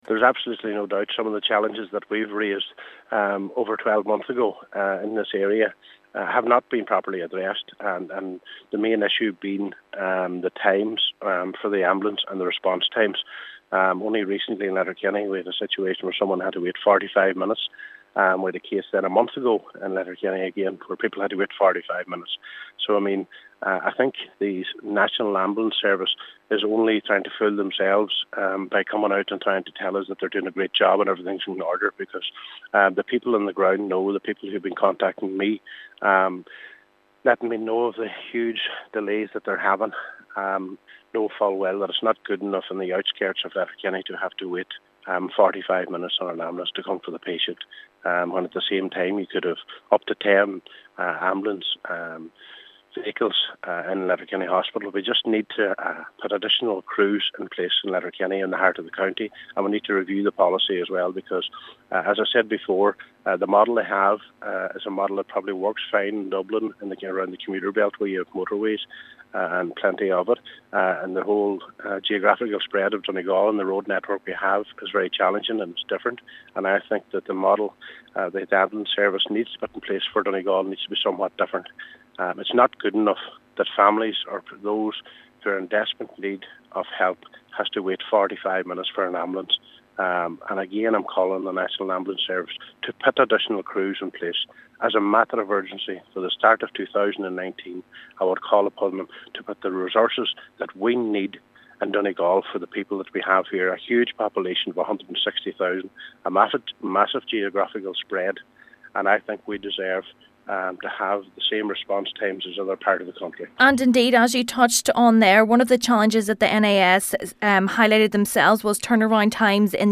Member of the Regional Health Forum Councillor Ciaran Brogan says it is vital management at the hospital and the ambulance service work in conjunction to find a solution: